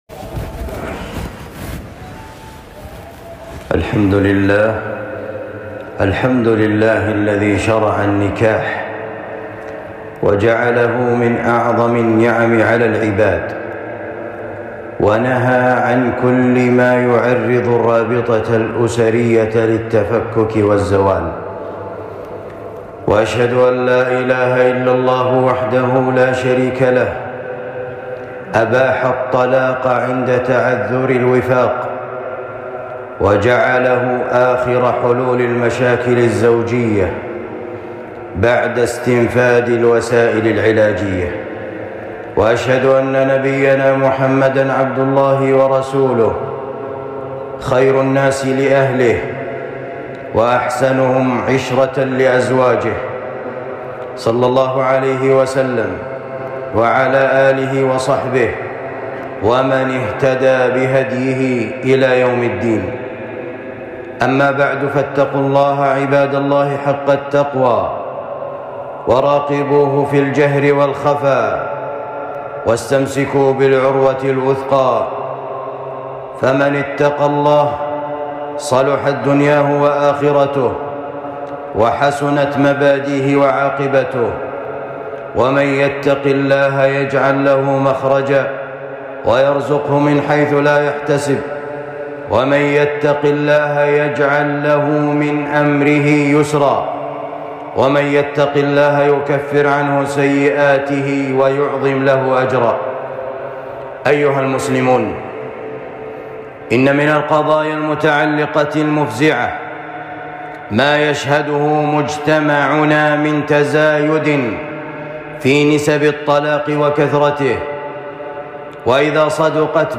خطبة بعنوان أسباب الطلاق وآثاره وعلاجه